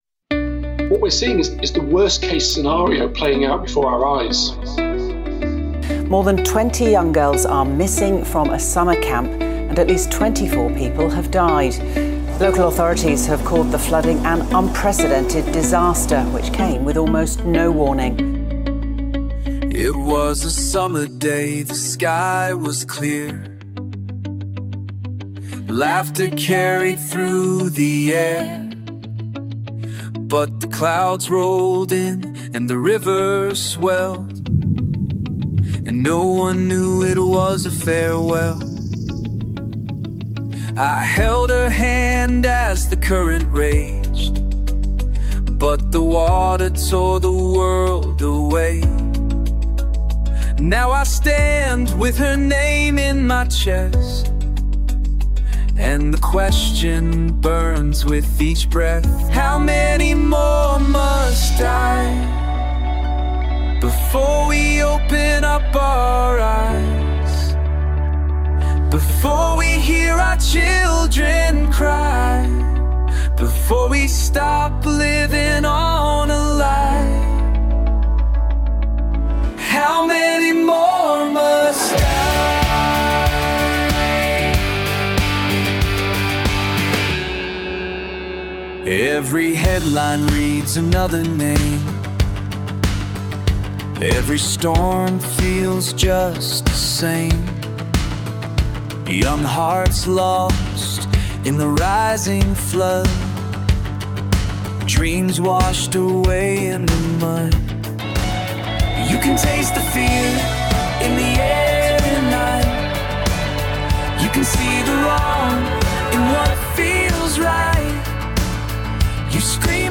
– How Many More Must Die: a poignant call to action inspired by flood victims and the fight for climate justice.
– An emotional song about loss, climate-driven disasters, and the desperate plea to wake up before more lives – especially children’s – are lost.